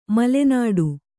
♪ male nāḍu